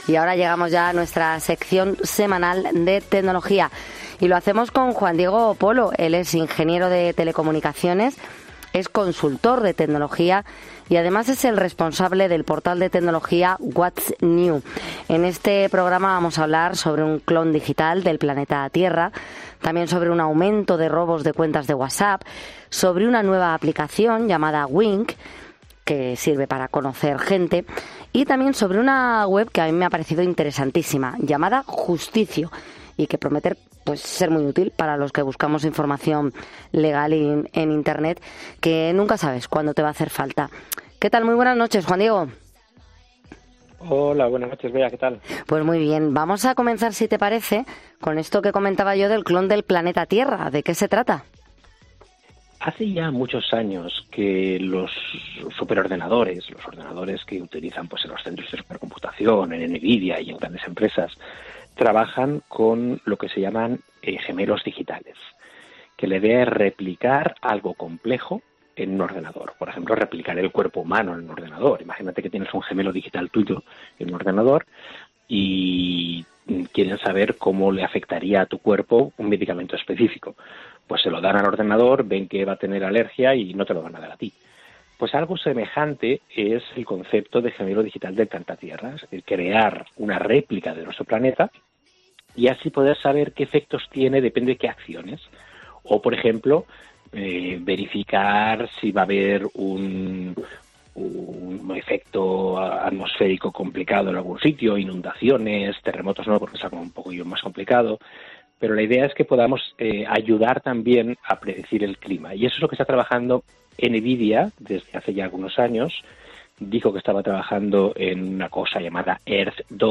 En nuestra sección semanal de tecnología hablamos con nuestro experto